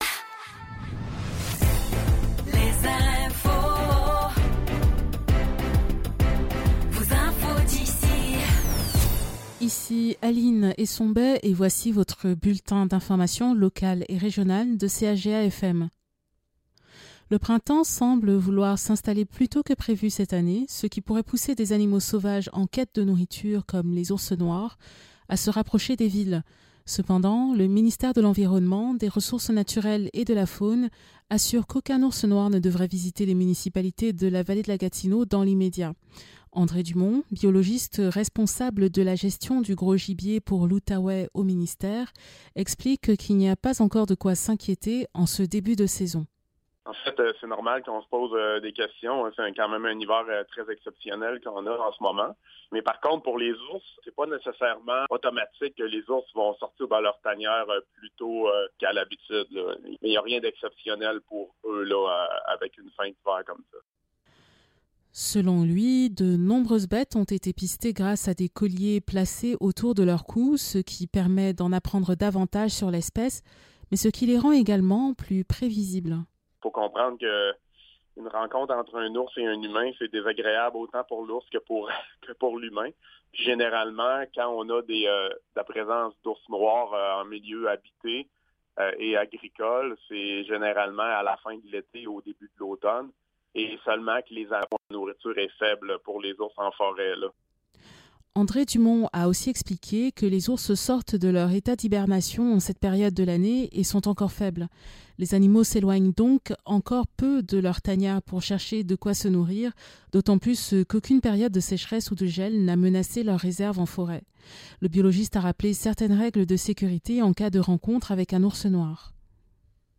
Nouvelles locales - 11 mars 2024 - 12 h